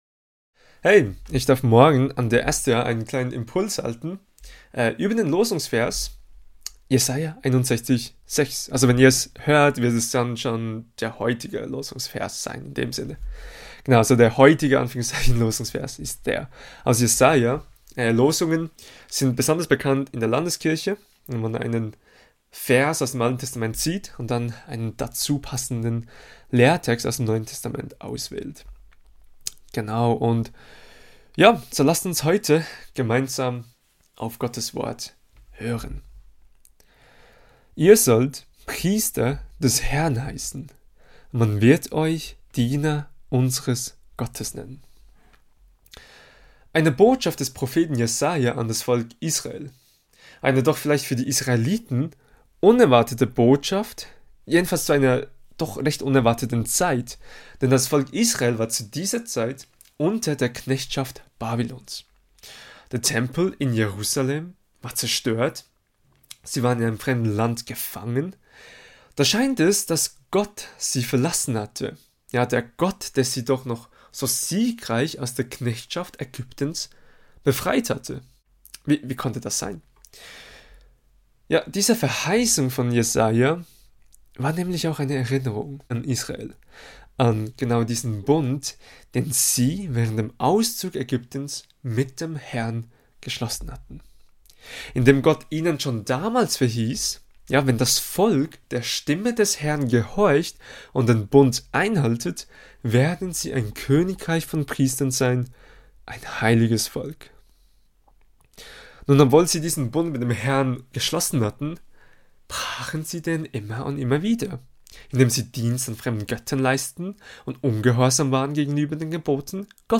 Kategorie: Impuls